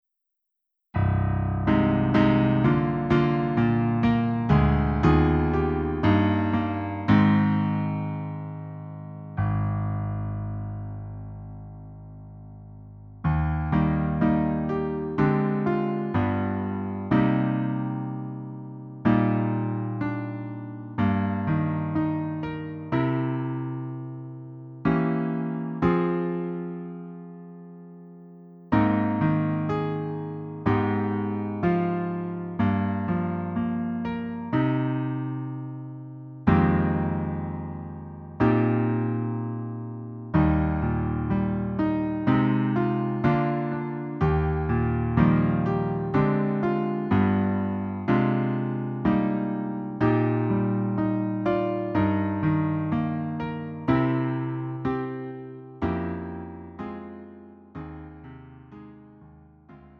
음정 -1키 4:11
장르 구분 Lite MR